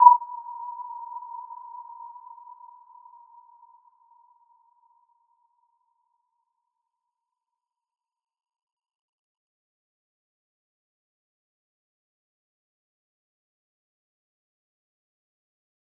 Warm-Bounce-B5-f.wav